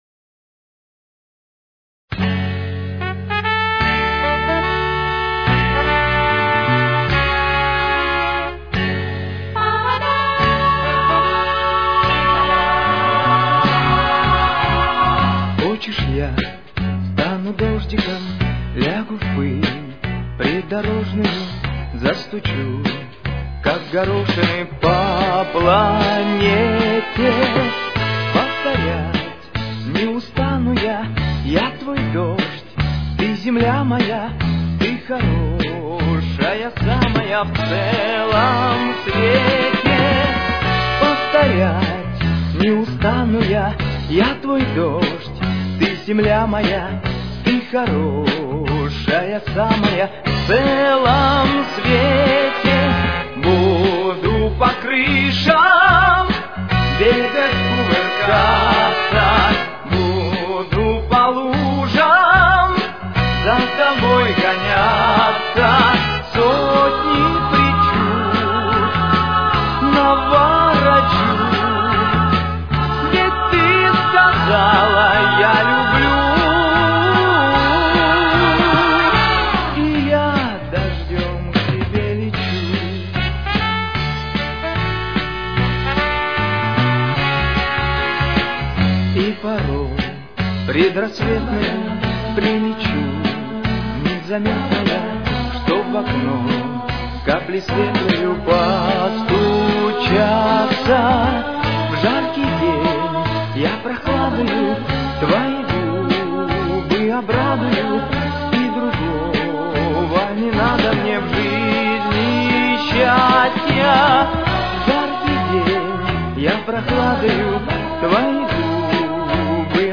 с очень низким качеством (16 – 32 кБит/с)
Тональность: Фа-диез минор. Темп: 153.